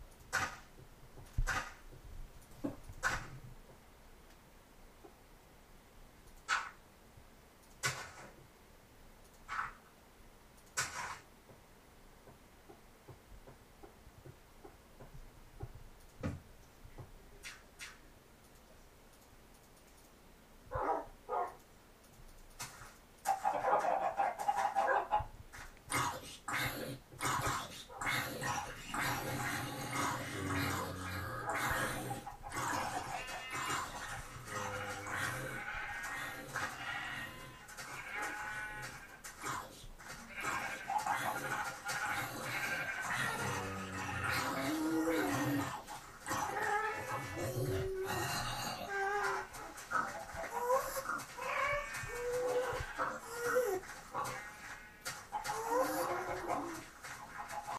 Location: Dorm room, computer 3/1/12 11:00pm
Sounds heard: Sound effects from my friend playing the game Minecraft. First you can hear clicking as structures are being built. Then you can hear many animals, including dogs, chickens, and sheep, as well as zombies and some kind of ghost creature.